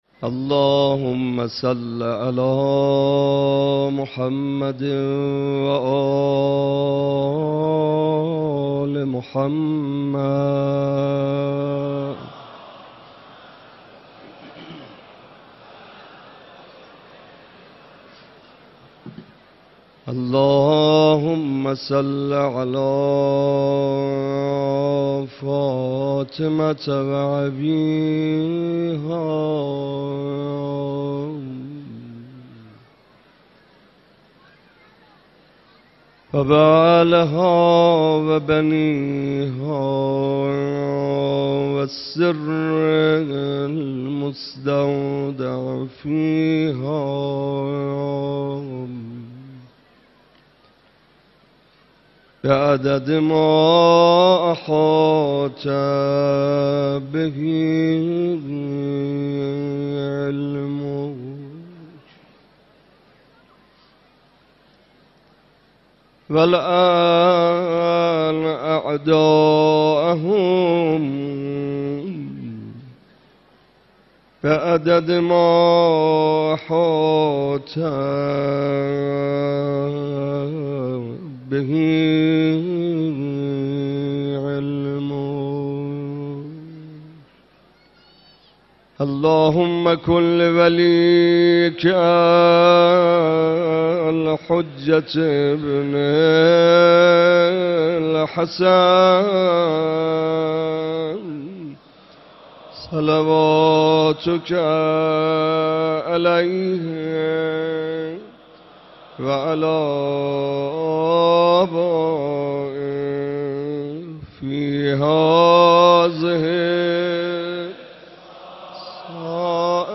روضه
روضه محمدرضا طاهری مداح اطلاعات عنوان : روضه مناسبت : شهادت امام رضا علیه‌السلام سال انتشار : 1393 مداح : محمدرضا طاهری قالب : روضه موارد مشابه صوتی سلام گرم مرا چون ز راه دور شنیدی روضه امام رضا روضه پایانی اراده خدا به اینه آقا روضه